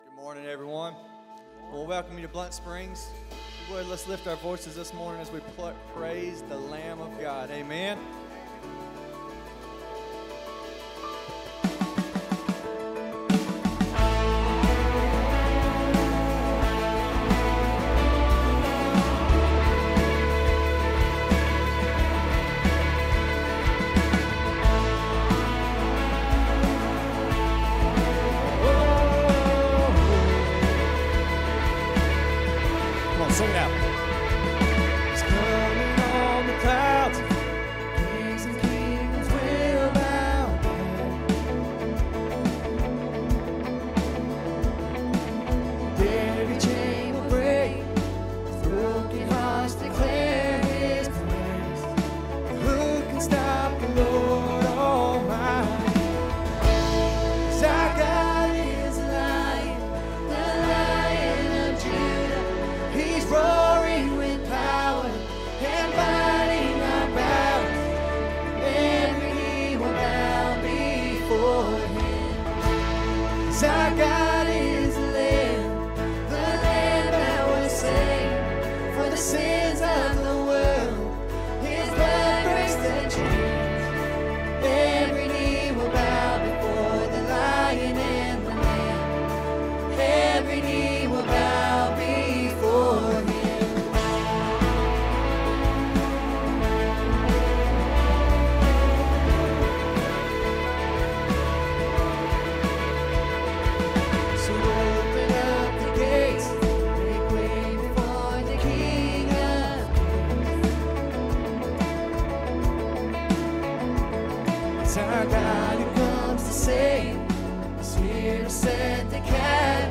Sunday morning sermon.